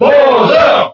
Category:Bowser (SSBB) Category:Crowd cheers (SSBB) You cannot overwrite this file.
Bowser_Cheer_French_SSBB.ogg